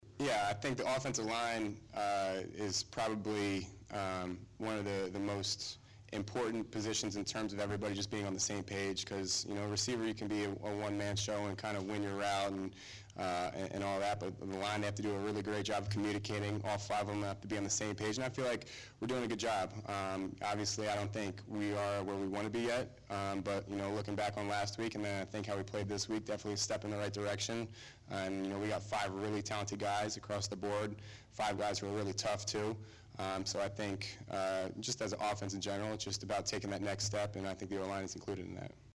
AUDIO EXCERPTS FROM KYLE McCORD PRESS CONFERENCE